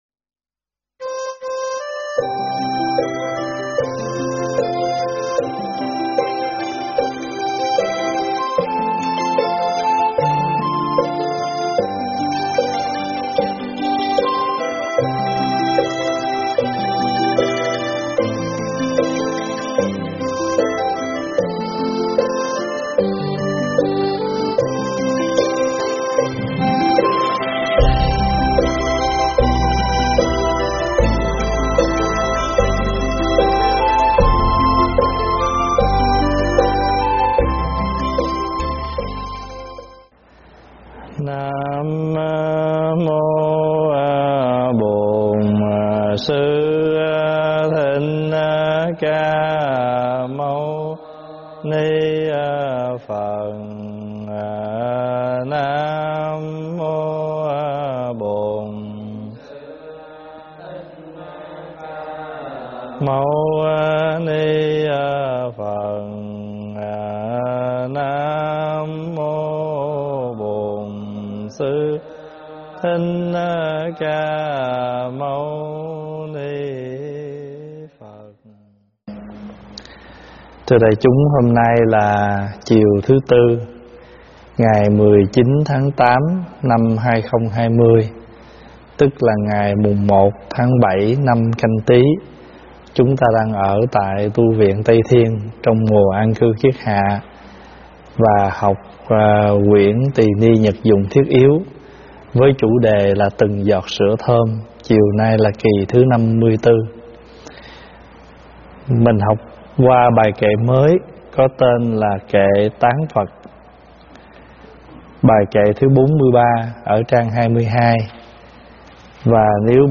giảng tại Tv Tây Thiên